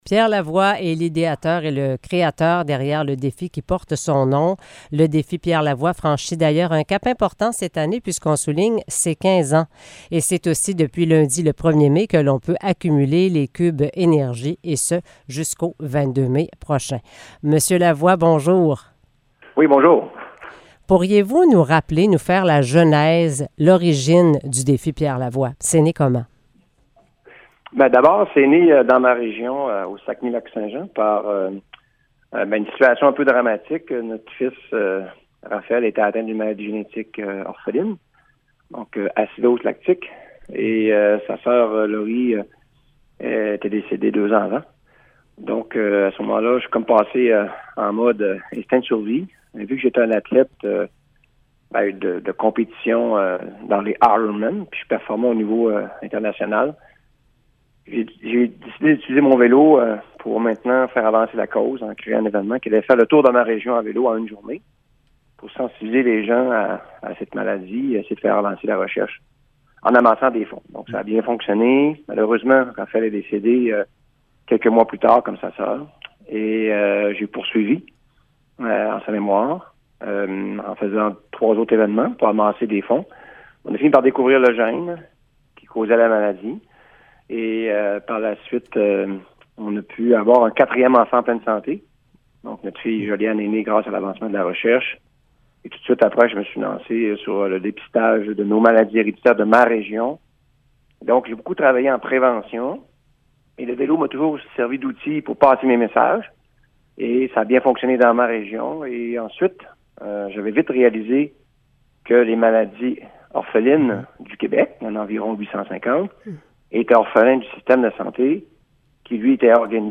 Entrevue avec le créateur du Défi Pierre Lavoie
entrevue-avec-le-createur-du-defi-pierre-lavoie.mp3